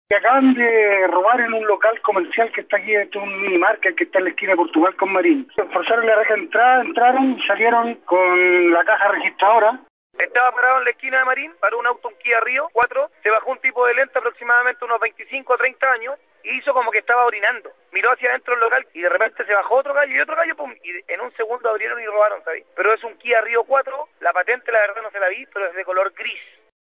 Auditores de El Trasnoche de La Radio entregaron antecedentes precisos del actuar de los asaltantes.